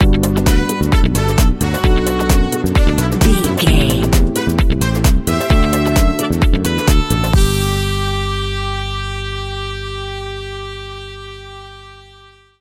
Ionian/Major
groovy
uplifting
energetic
bass guitar
brass
saxophone
drums
electric piano
electric guitar
disco
synth
upbeat
funky guitar
wah clavinet